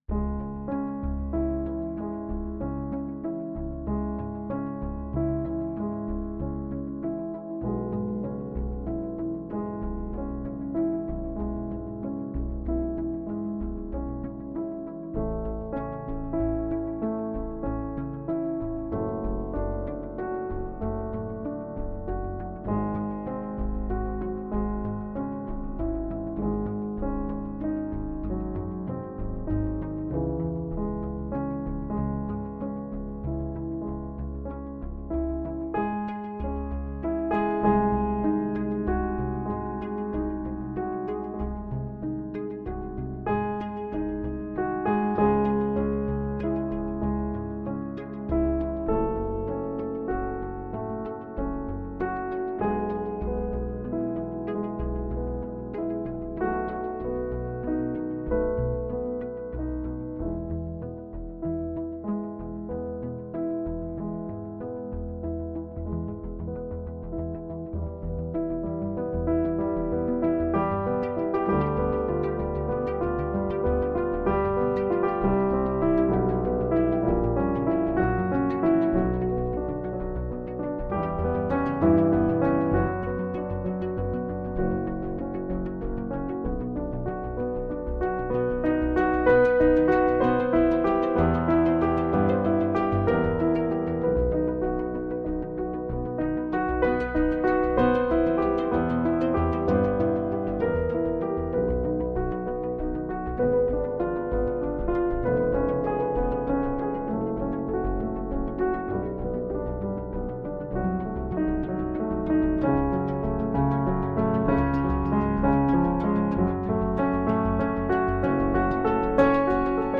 Μία πρόσεχα τον ήχο του πιάνου και μία του synthesizer. Μάλιστα το πεντάλ το έβαλα να λειτουργεί μόνο στο πιάνο.
4. Δυσκολεύτηκα να ταιριάξω drums.
6. Ο ήχος του synthesizer είναι προγραμματισμένος από το μηδέν.